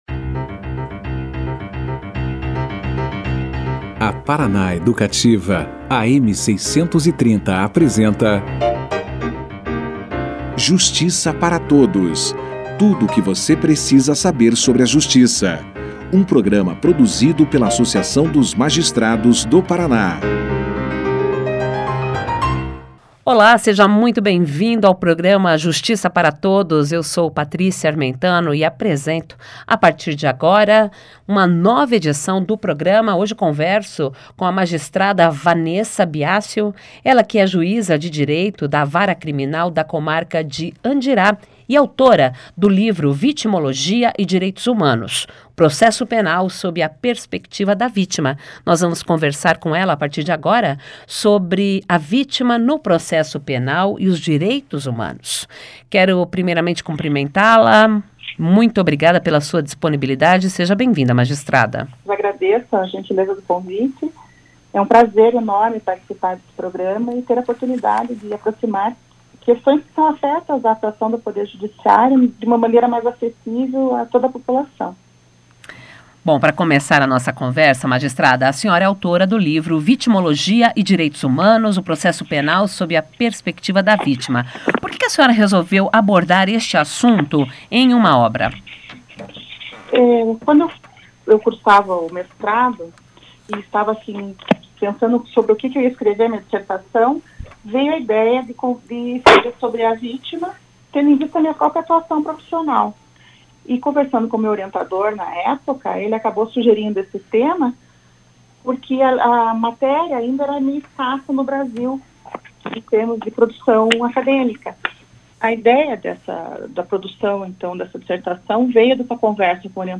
>>Clique Aqui e Confira a Entrevista na Integra<<
O Programa Justiça Para Todos desta quinta-feira, 04 de junho, entrevistou a juíza da Vara Criminal da Comarca de Andirá, Vanessa de Biassio.